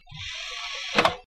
CD在驱动器中旋转
描述：将CD插入磁盘驱动器。